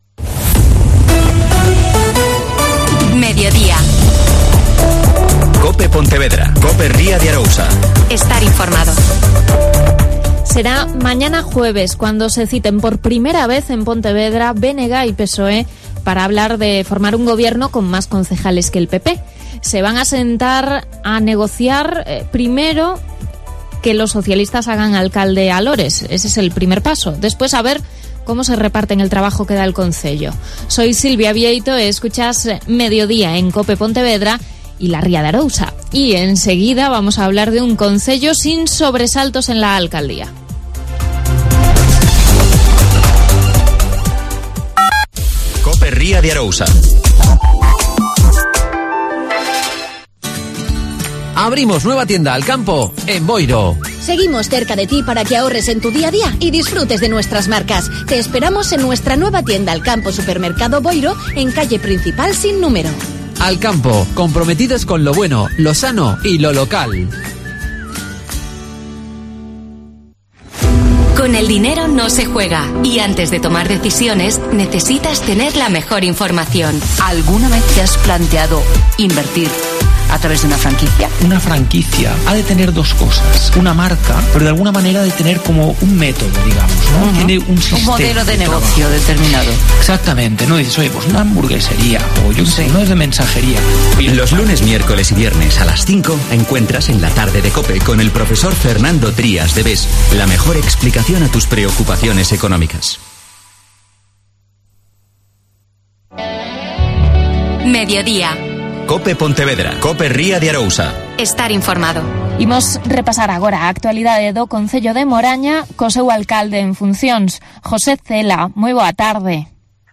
AUDIO: José Cela. Alcalde en funciones de Moaña.